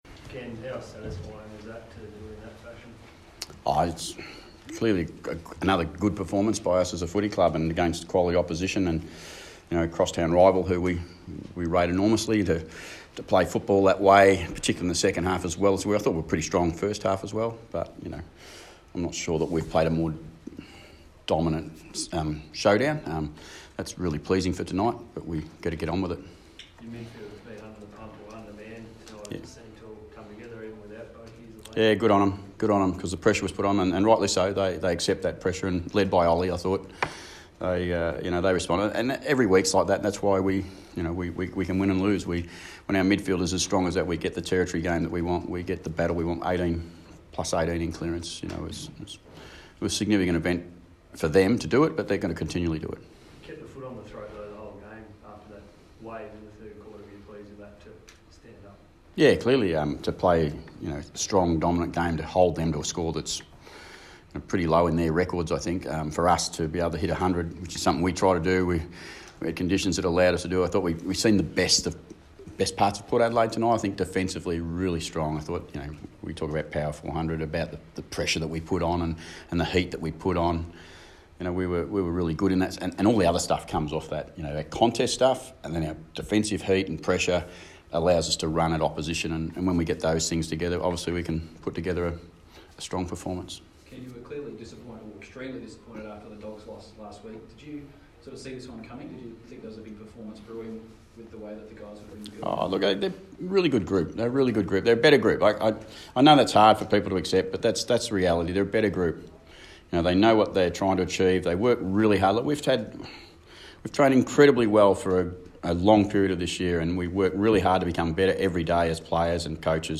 Ken Hinkley press conference - Saturday 6 July, 2019